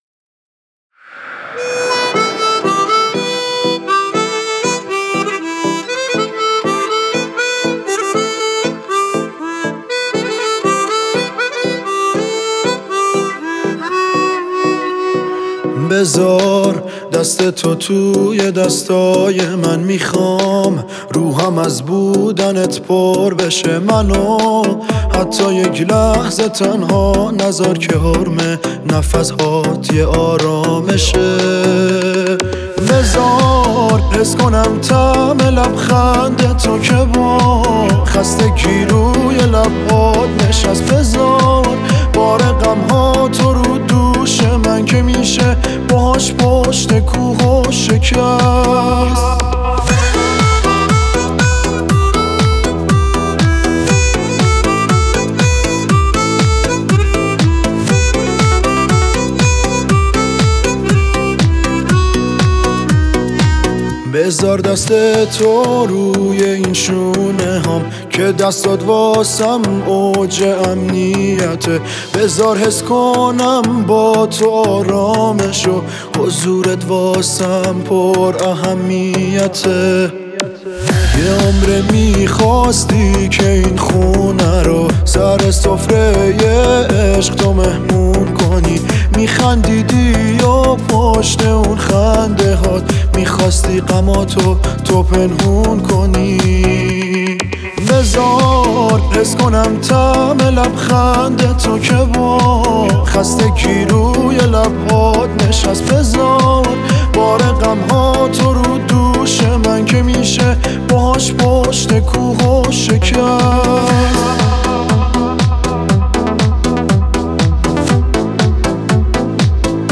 хонандаи эронӣ